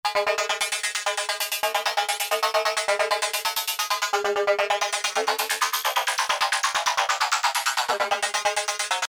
C02 - Krazy Arpy 1 Put this arpeggiated pattern in sync with your beat for more interesting textures.